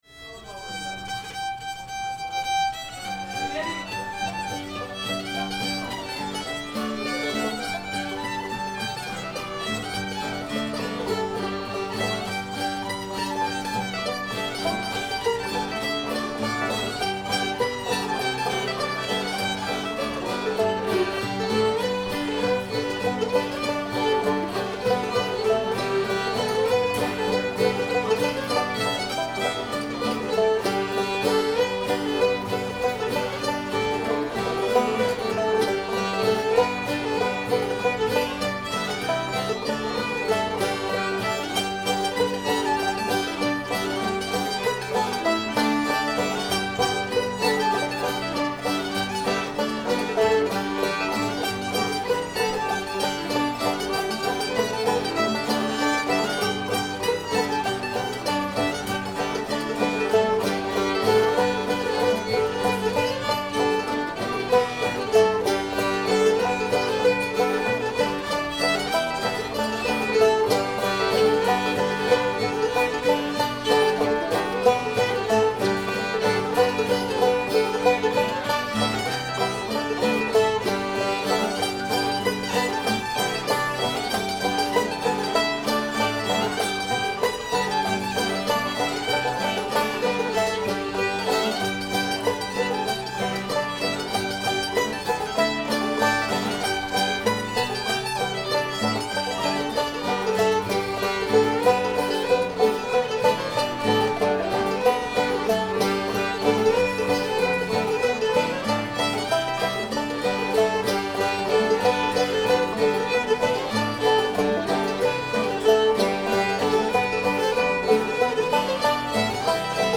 hezekiah [G]